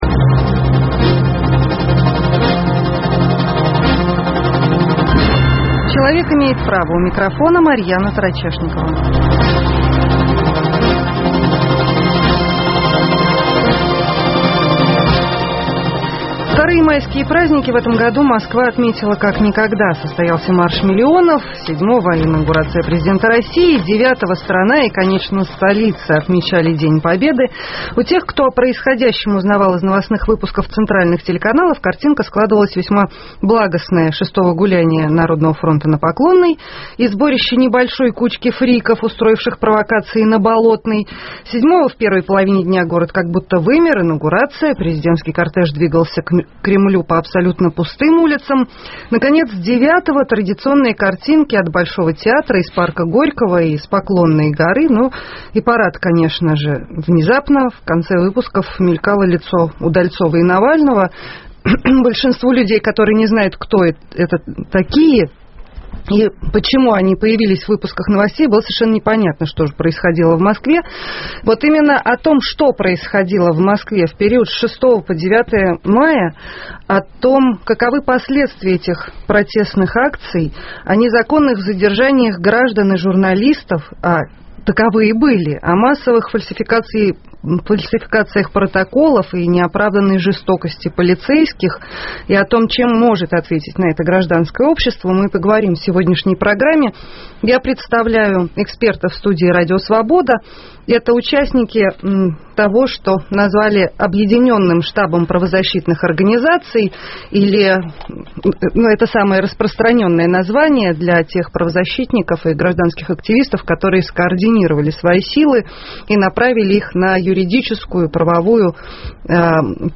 Незаконные задержания граждан и журналистов, массовые фальсификации протоколов и неоправданная жестокость полицейских, чем ответит на это гражданское общество? В студии РС члены Объединенного штаба правозащитных организаций.